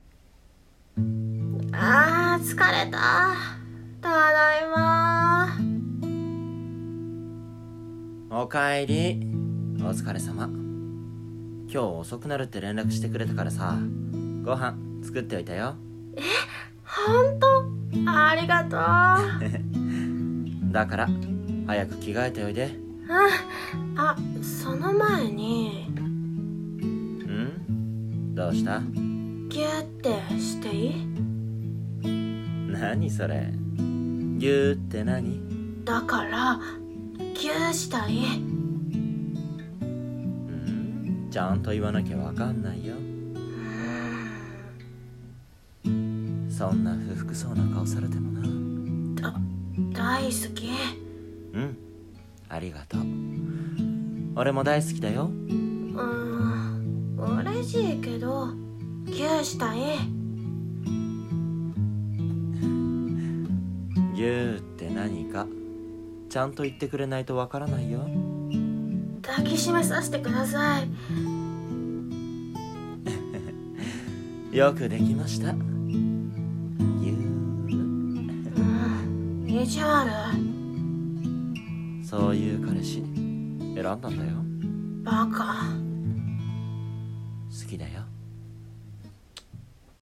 【声劇】優しいけどいじわるな彼氏